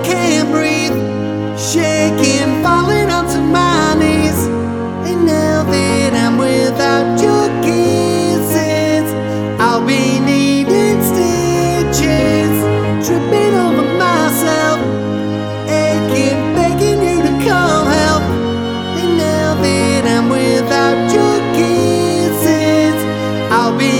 Up 4 Female Key